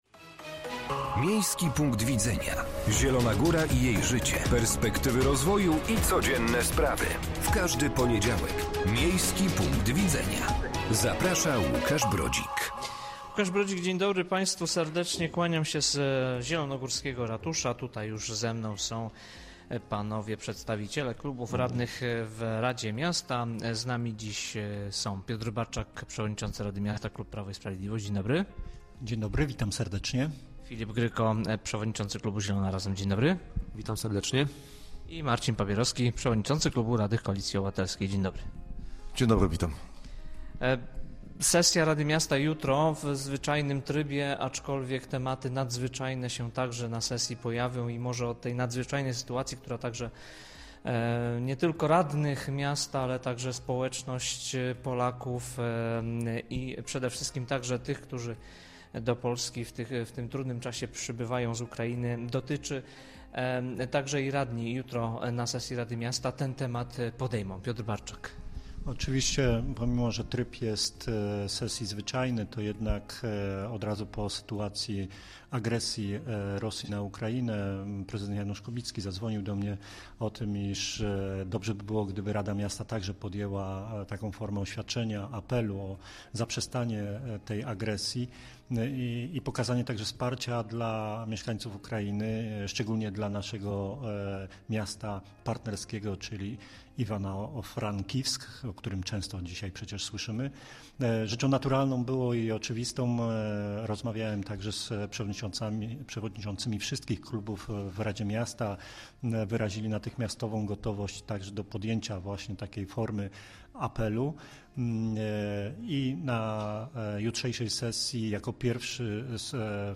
Gośćmi audycji byli: Piotr Barczak – przewodniczący rady miasta, klub Prawo i Sprawiedliwość, Filip Gryko – przewodniczący, klubu Zielona Razem,
Piotr Barczak – przewodniczący rady miasta, klub Prawo i Sprawiedliwość, Filip Gryko – przewodniczący, klubu Zielona Razem, Marcin Pabierowski – przewodniczący klubu radnych Koalicji Obywatelskiej.